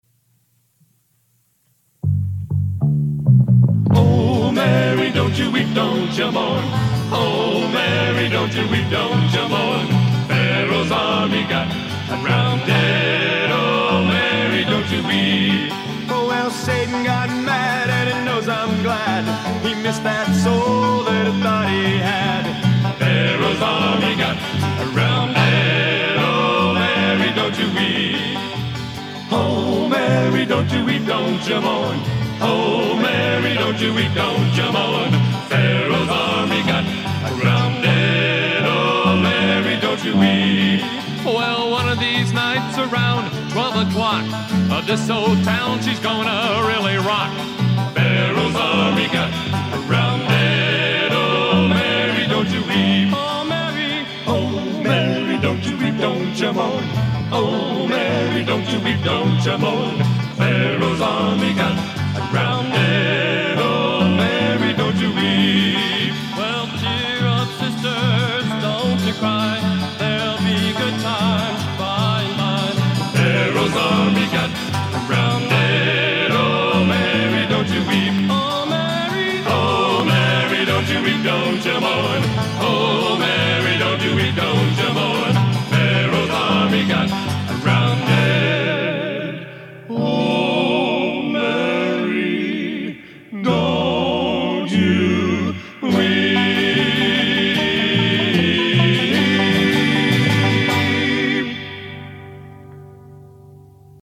Genre: Gospel Sacred | Type: Studio Recording